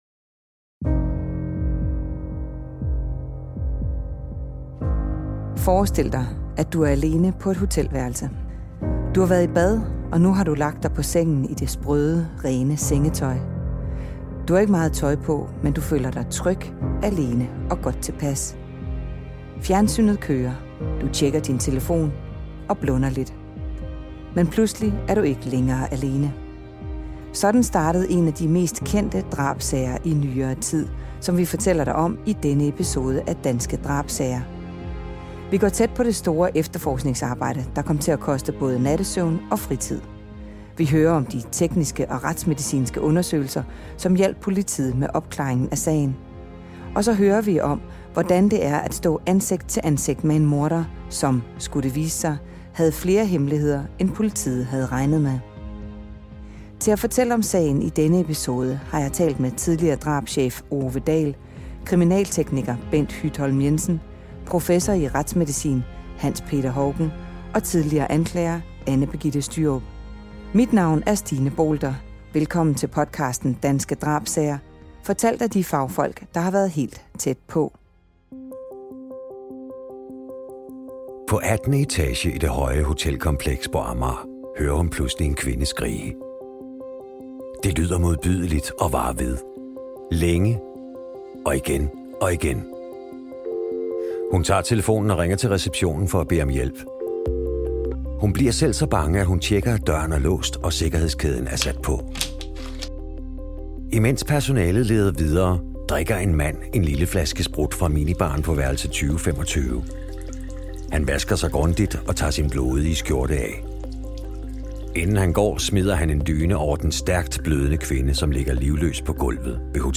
Som du vil høre i afsnittet, så fortæller eksperterne om, hvordan det er at lede efter en morder på timevis af videoovervågning, gennemgå et blodigt gerningssted og sikre spor på den dødes krop.